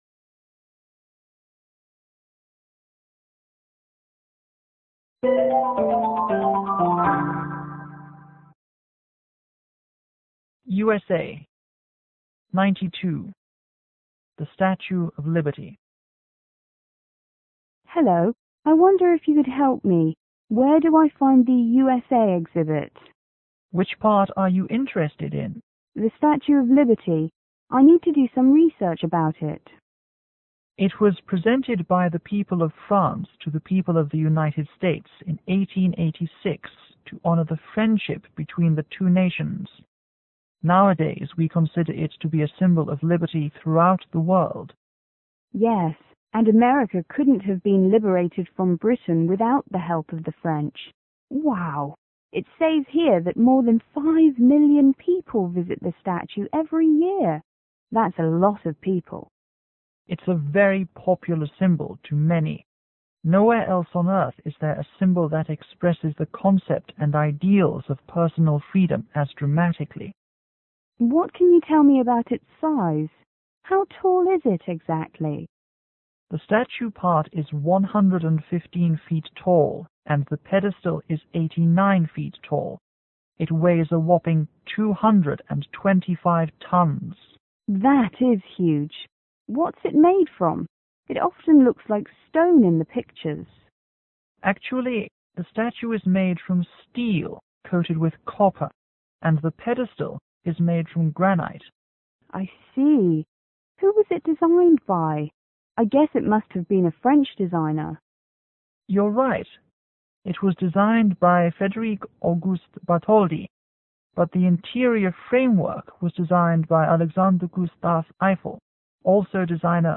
M: Museum            T: Tourist